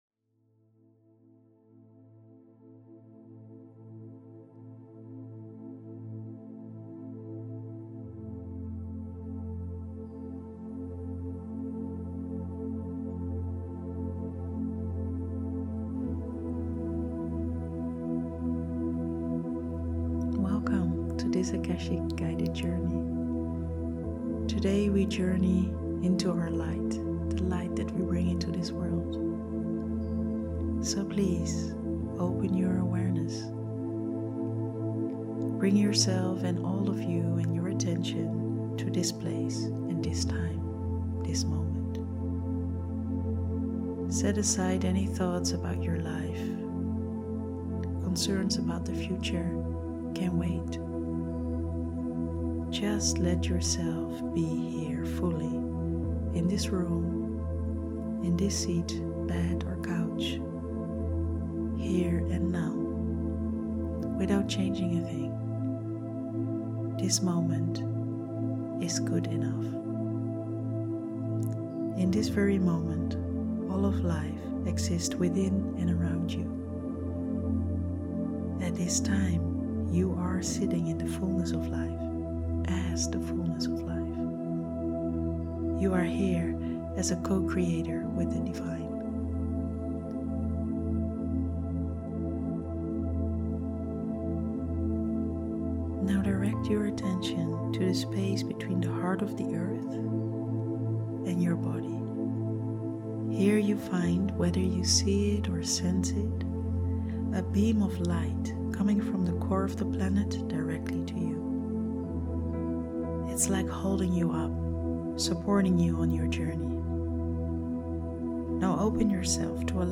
Akasha Guided Journey